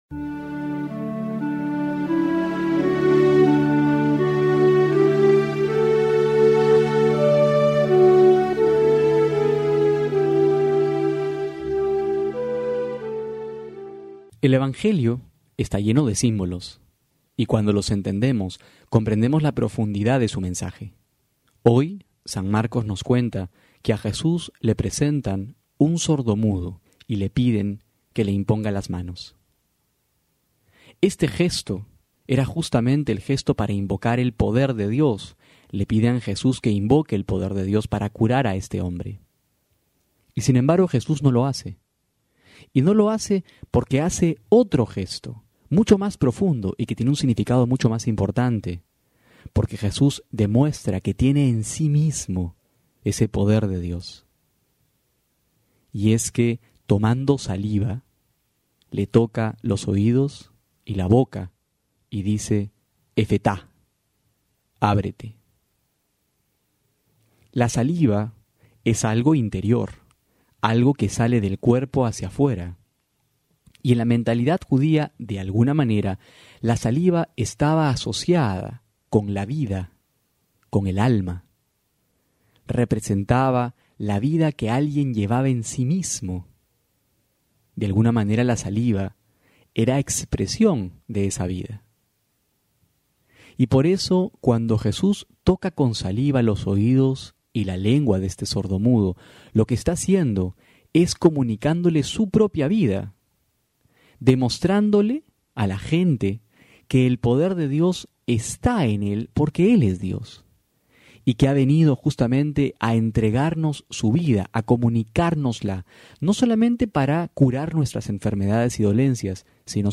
febrero10-12homilia.mp3